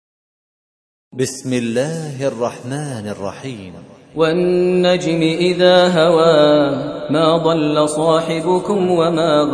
53. Surah An-Najm سورة النجم Audio Quran Tarteel Recitation
Surah Sequence تتابع السورة Download Surah حمّل السورة Reciting Murattalah Audio for 53. Surah An-Najm سورة النجم N.B *Surah Includes Al-Basmalah Reciters Sequents تتابع التلاوات Reciters Repeats تكرار التلاوات